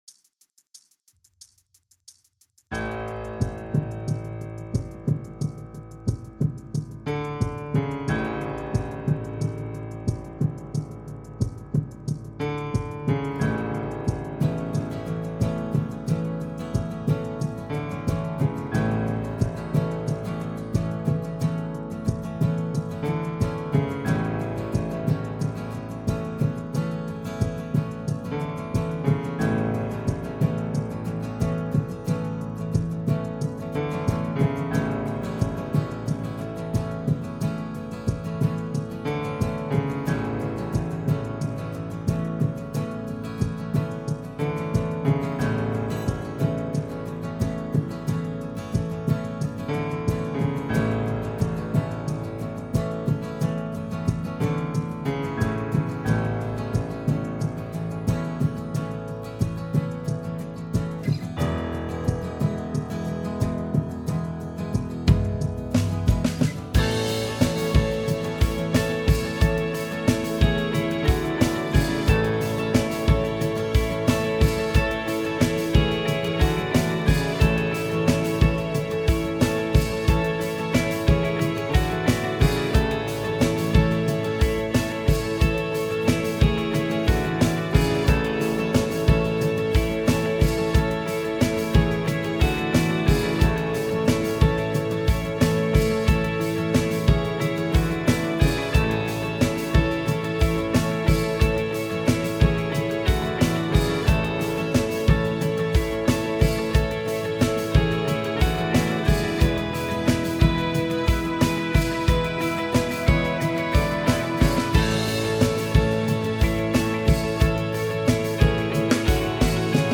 BPM : 90
Tuning : E
Without vocals